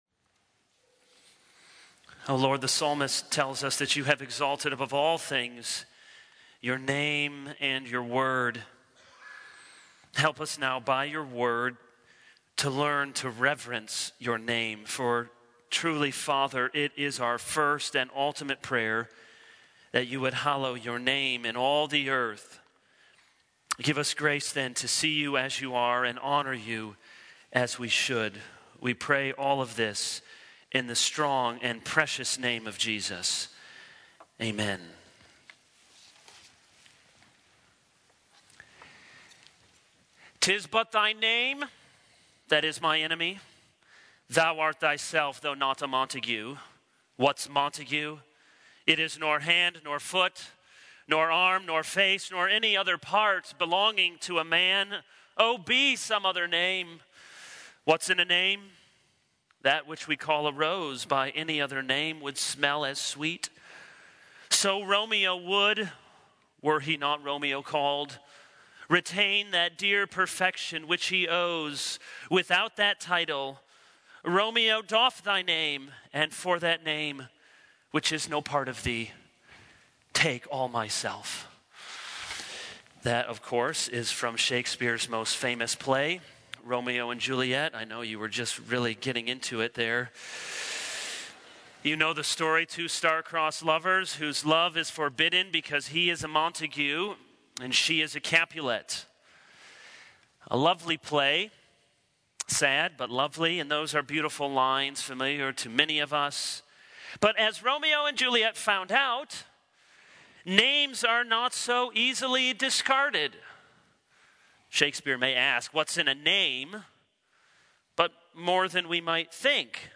This is a sermon on Exodus 20:7.